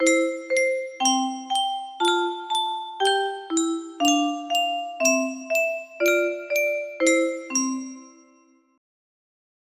Unknown Artist - Untitled music box melody